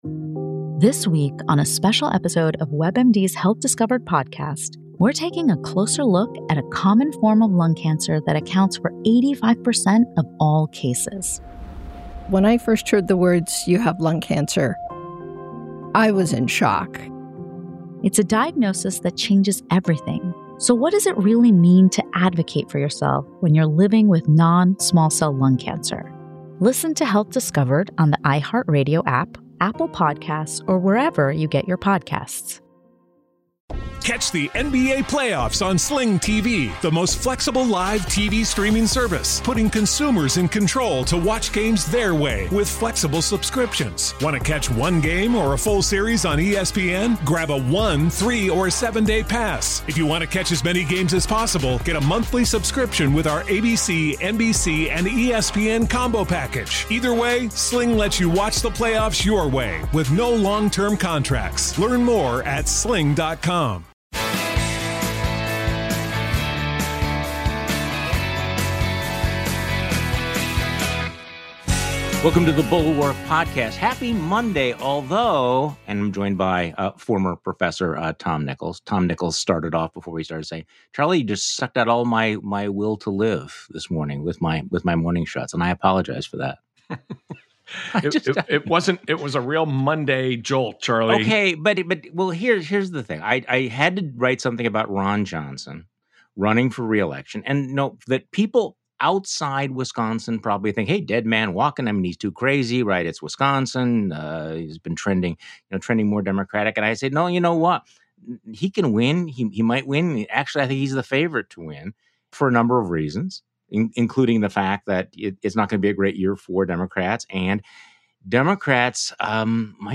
But the only thing that matters now is who's on the side of the Constitution, and who isn't. Tom Nichols joins Charlie Sykes on today's podcast.
Special Guest: Tom Nichols.